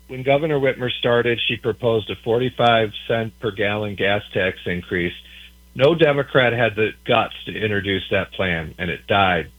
AUDIO: House Speaker Hall reacts to Governor Whitmer’s road funding plan